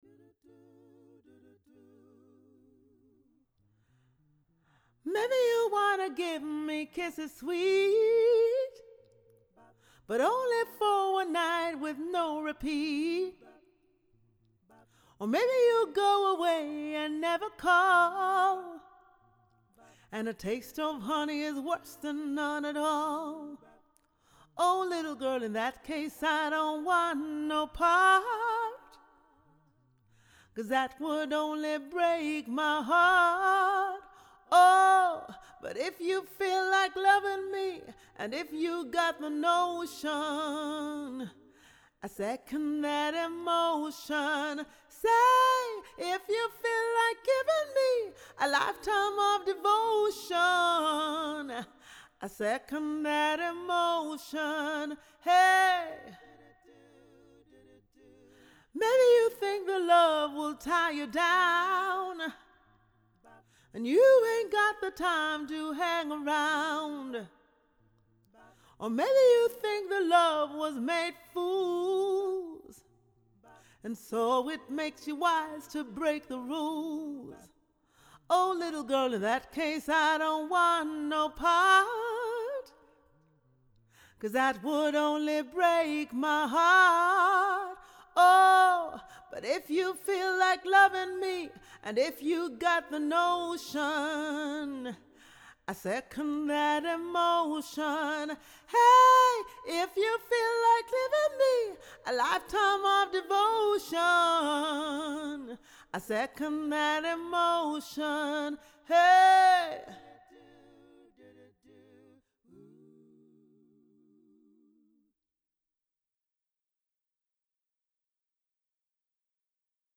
Genre: Choral.